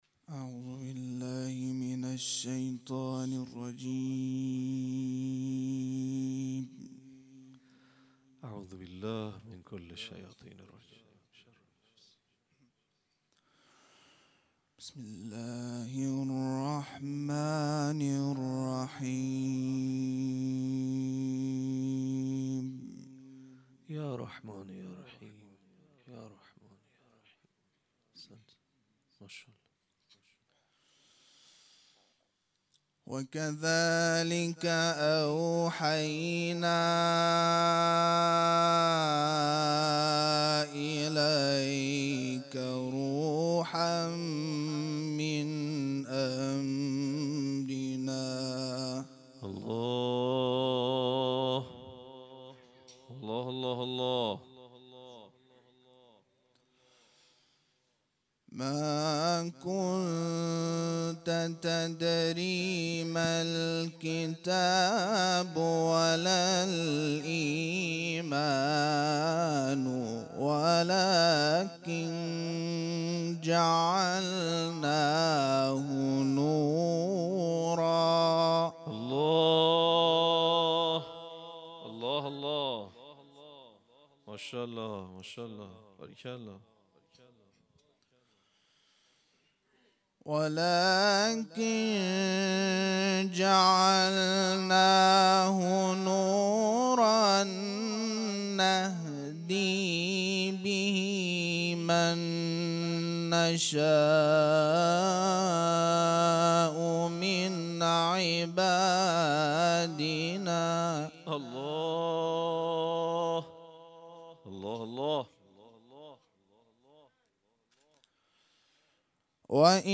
صوت اولین کرسی های تلاوت قرآن کریم